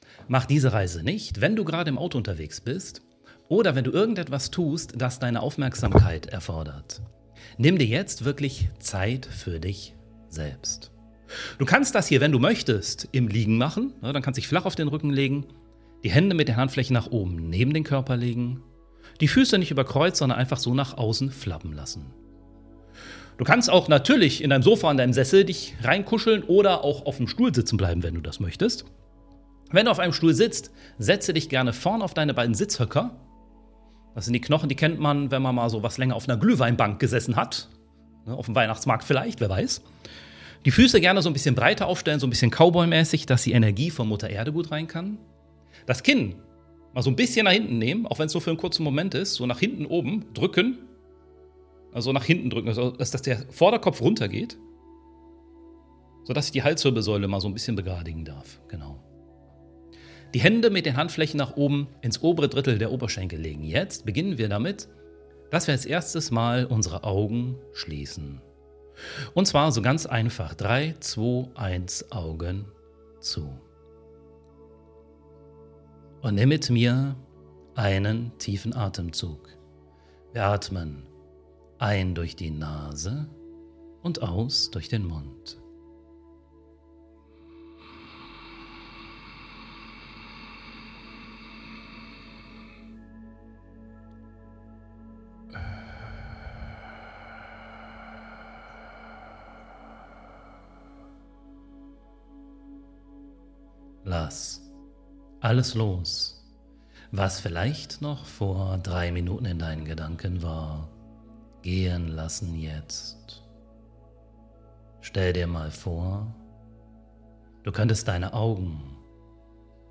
Es ist keine gewöhnliche Podcast-Folge, sondern eine geführte Meditationsreise.